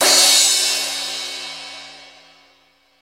• Cymbal Sound Clip C# Key 04.wav
Royality free cymbal tuned to the C# note. Loudest frequency: 5421Hz
cymbal-sound-clip-c-sharp-key-04-XZw.wav